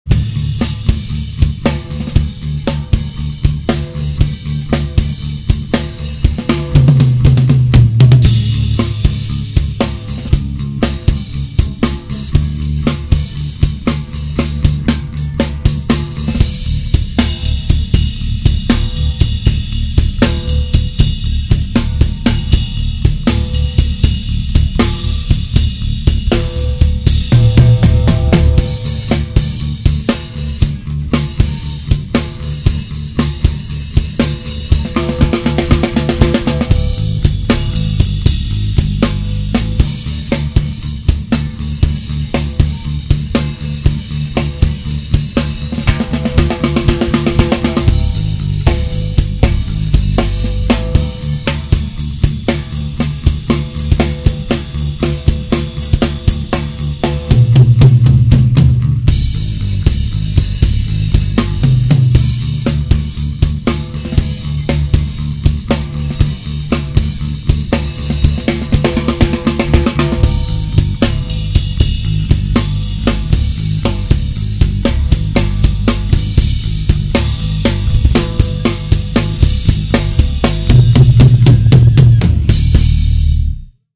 Náhrávky nejsou normalizovány, nejsou přidány žádné efekty.
DÍL PRVNÍ rytmické základy - bicí a basa